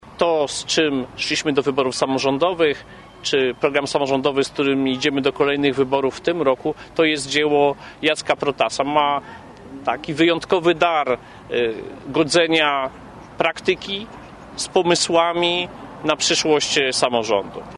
Kandydata Koalicji Europejskiej wspierali w Ełku między innymi: parlamentarzysta Tomasz Siemoniak, były Minister Obrony Narodowej, posłowie Paweł Papke i Janusz Cichoń oraz ełccy działacze Koalicji.